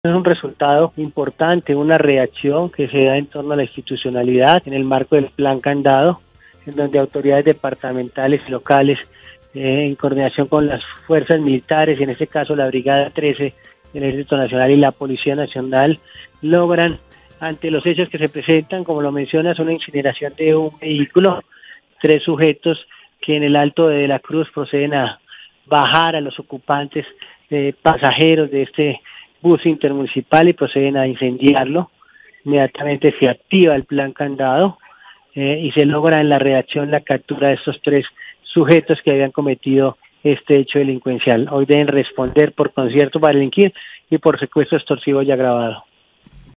El coronel Necton Lincon Borja, Comandante de la Policía en Cundinamarca, informó que fueron tres sujetos los reseñados, uno colombiano y dos de nacionalidad venezolana. El hecho vandálico se produjo cerca al peaje Chusacá.